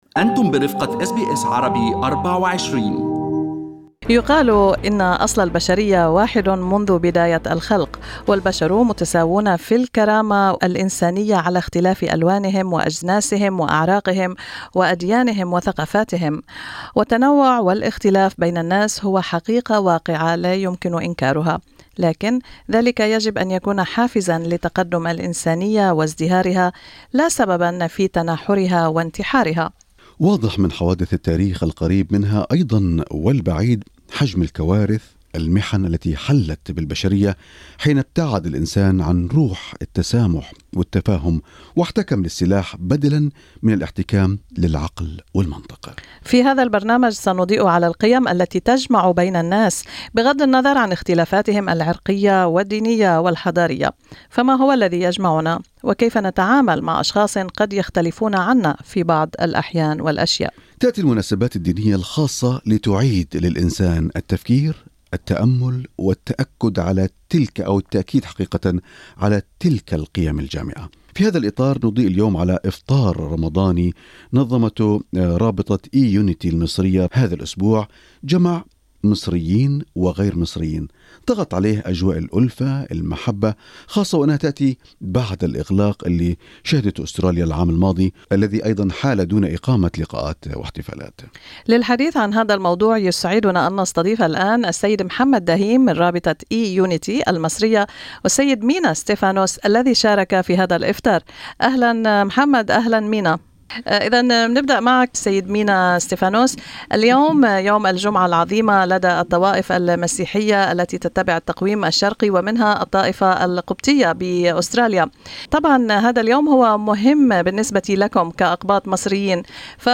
بمناسبة عيد القيامة المجيد لدى الطوائف المسيحية الشرقية وشهر رمضان المبارك مصريان يتحدثان عن القواسم المشتركة بينهما ويتبادلان المعايدات.